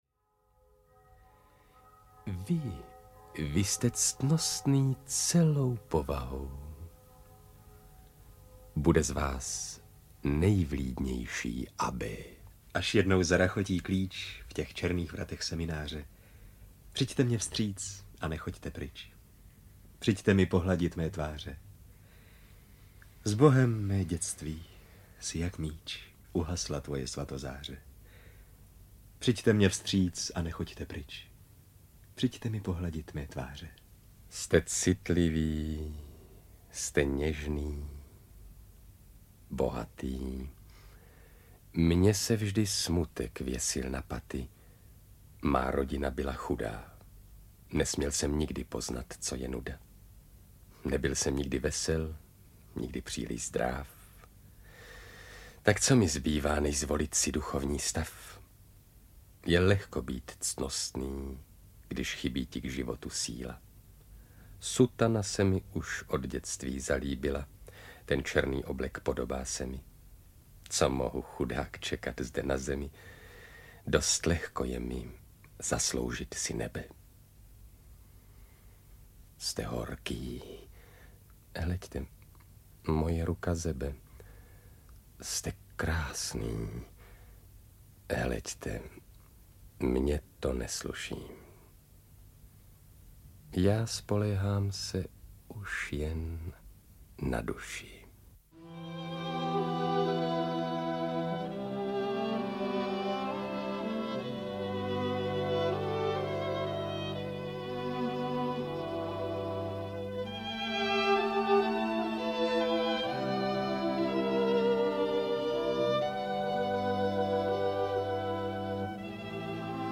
Manon Lescaut - Výběr scén - audiokniha obsahuje scény ze slavné básnické hry Vítězslava Nezvala
Ukázka z knihy